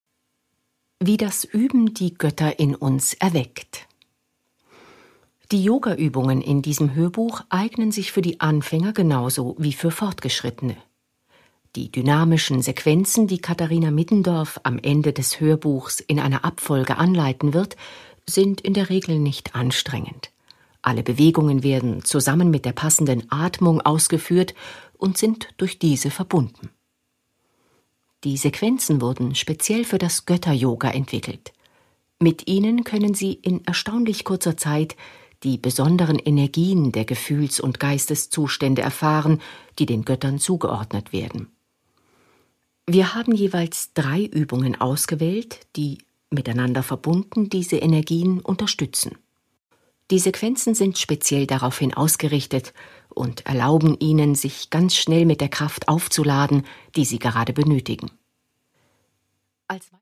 Produkttyp: Hörbuch-Download
Fassung: Autorisierte Lesefassung mit Musik